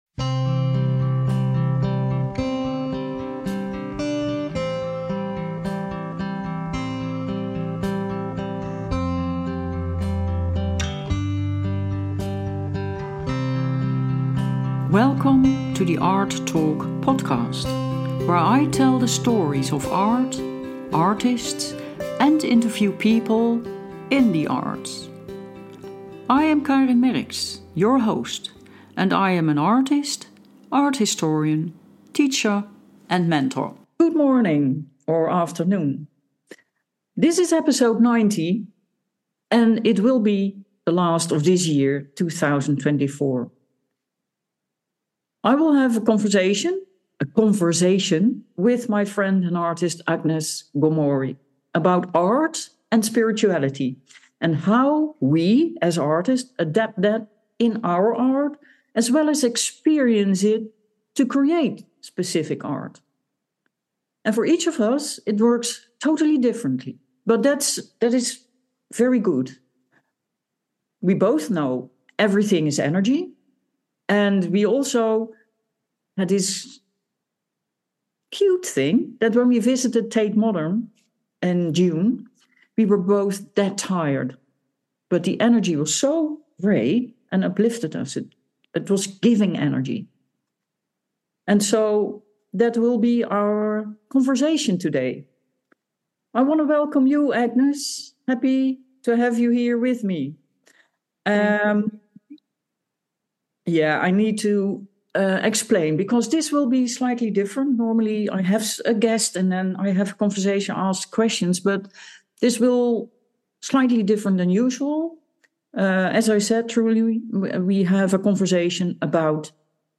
A Conversation
This will, however, be slightly different than usual, we truly have a conversation about what spirituality means to us and how it plays into our lives and art.